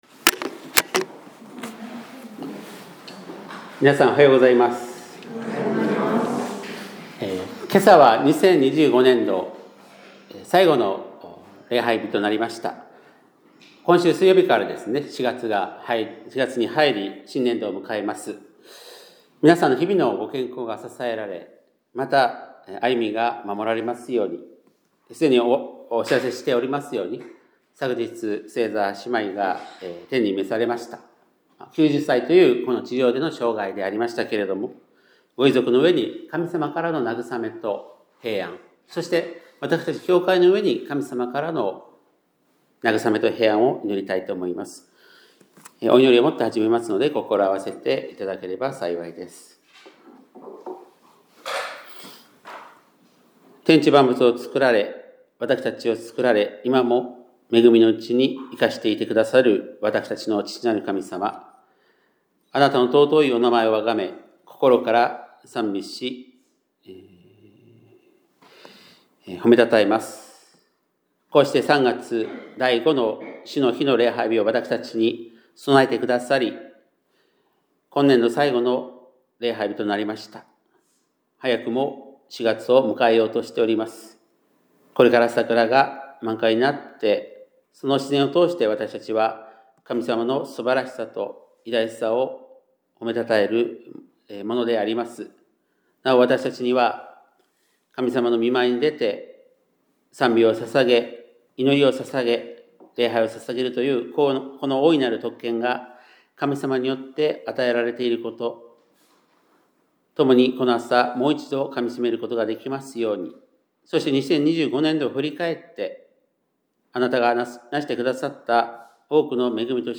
2026年3月29日（日）礼拝メッセージ